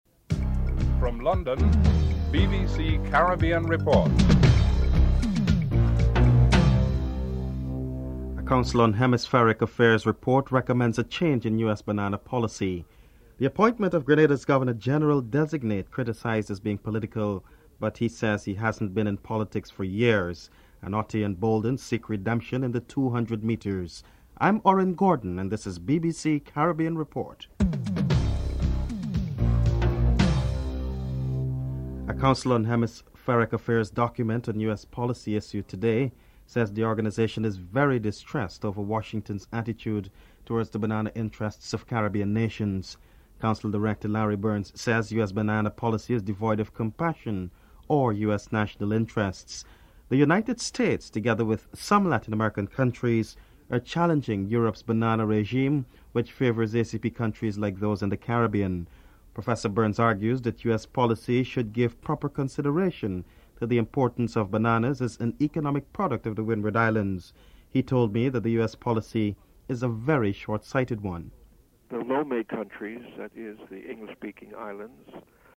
1. Headlines (00:00-00:31)
4. The appointment of Grenada's Governor General designate is criticised as being political but he says he has not been in politics for years. Political Leader of the National Democratic Labour Party Dr Francis Alexis is interviewed (06:21-12:30)